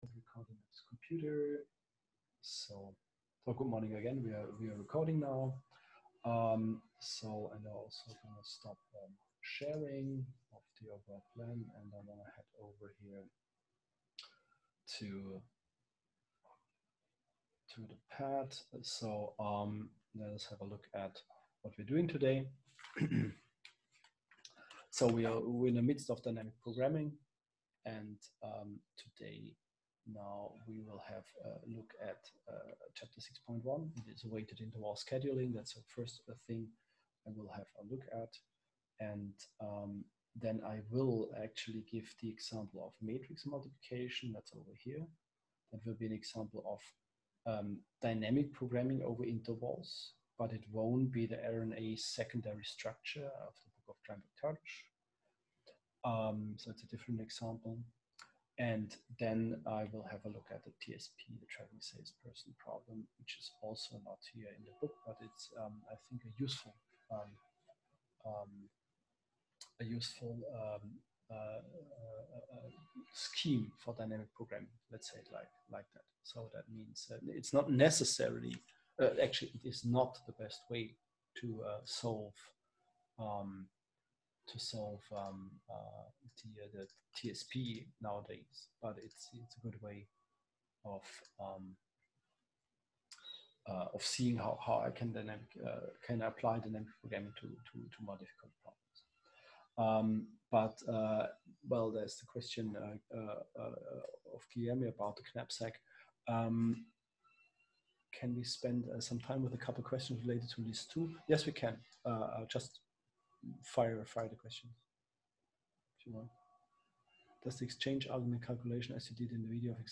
Online lecture